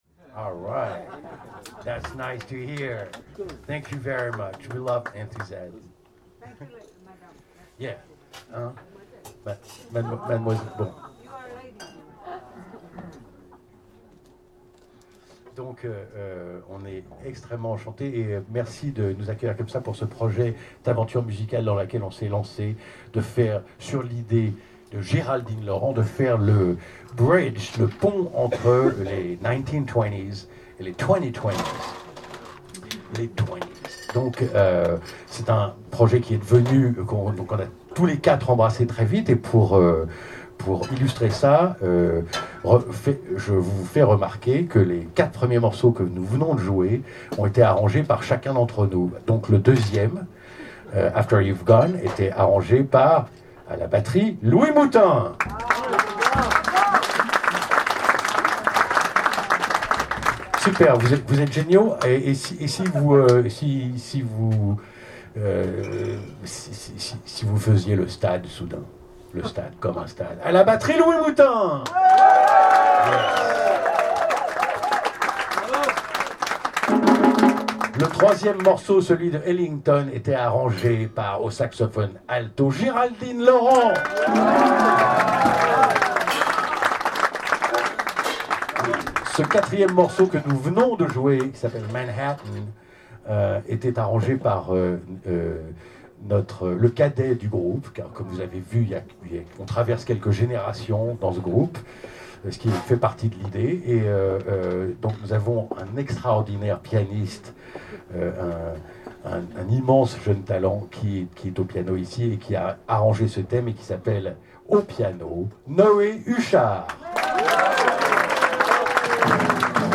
Live jazz from one of Paris' top night spots
the excellent quartet
Recorded in Paris, France in February 2026